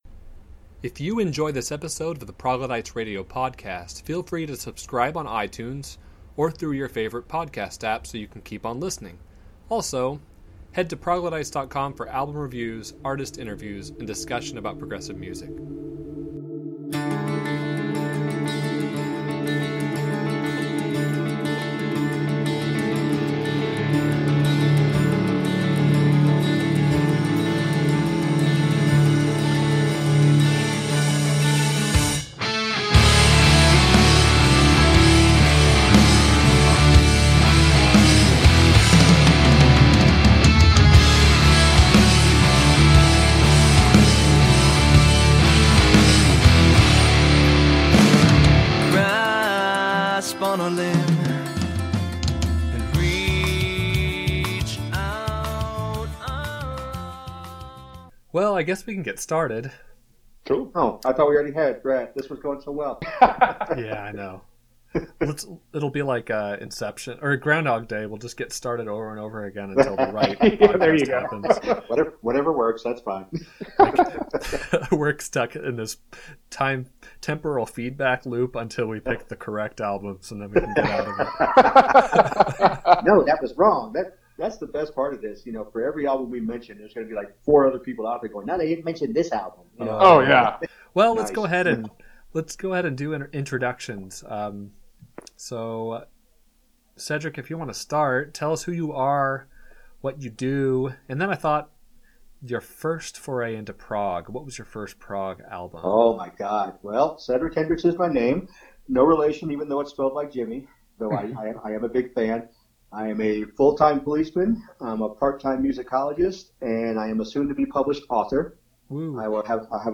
The Proglodytes Cave Council was created to answer all of progressive rock’s lingering questions, in a talk show format.